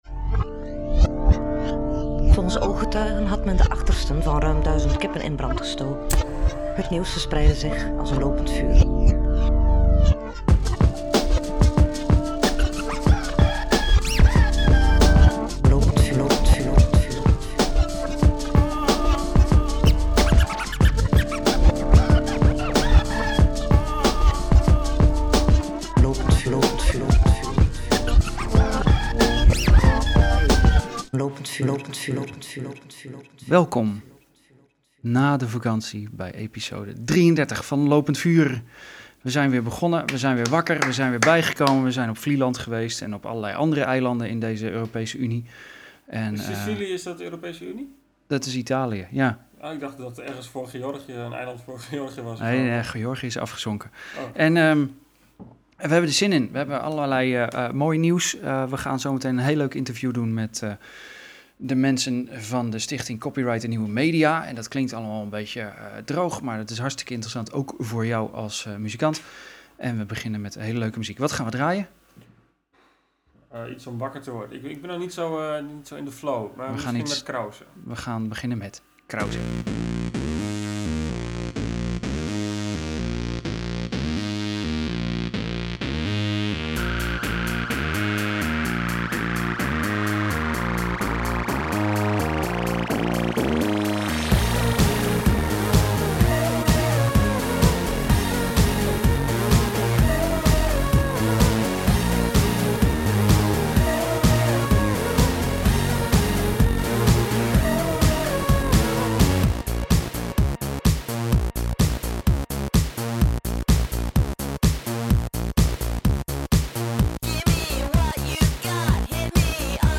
Na vakanties op verschillende eilanden is het weer hoog tijd voor een podcast met fijne muziek en een goed interview.